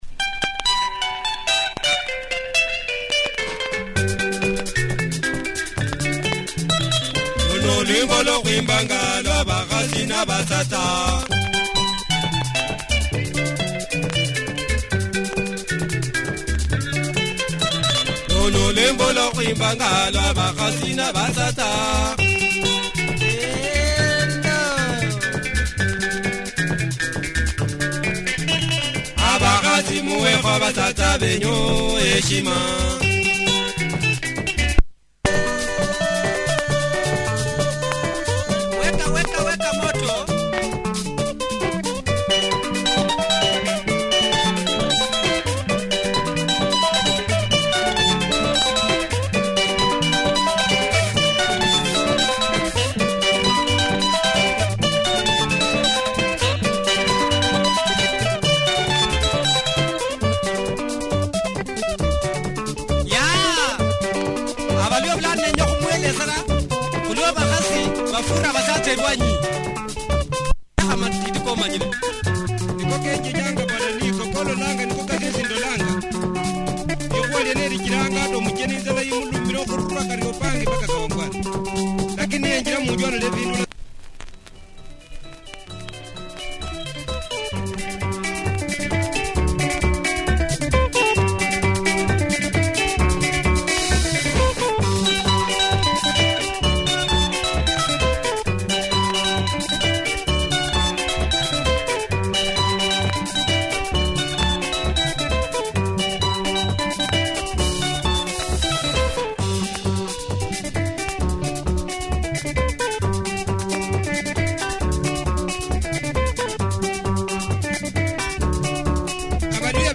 Superb Luhya benga